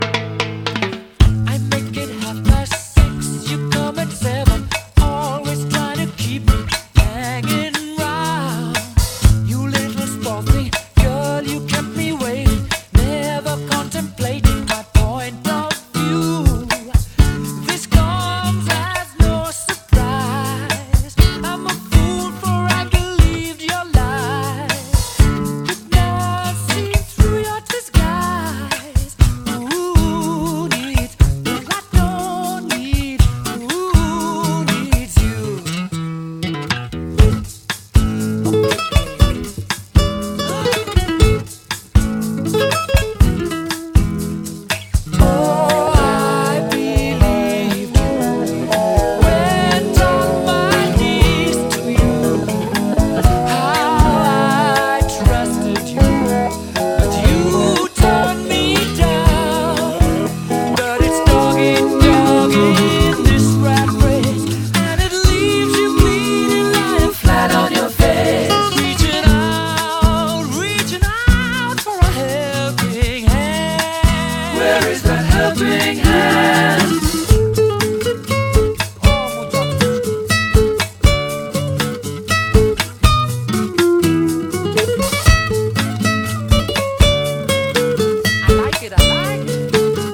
ROCK / 60'S / PSYCHEDELIC ROCK (US)
ブラス・セクションやストリングスを大胆に導入した69年発表の4TH！